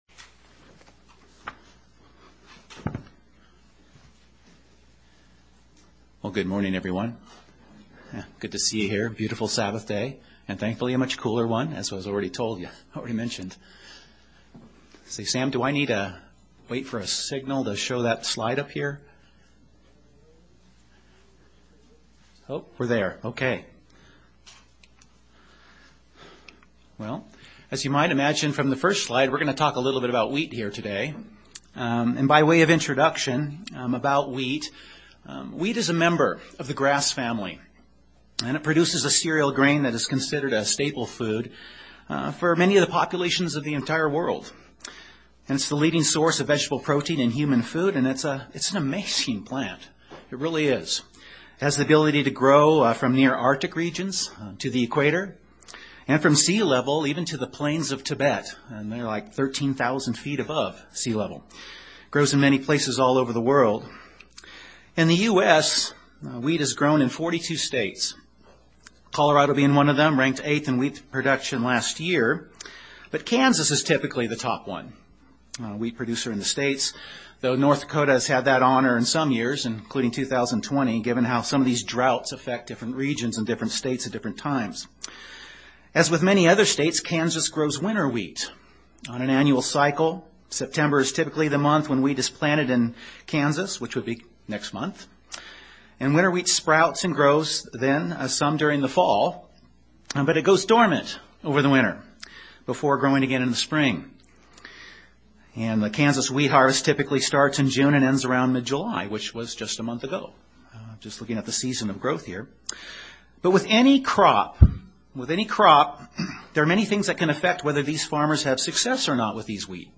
The bible has many examples of farming and harvests and how they relate to spiritual matters. This sermon focuses on two parables - the parable of the sower and the parable of the wheat and the tares - to show how God is growing spiritual wheat; including the environment in which they are to grow and thrive in (Matthew 13).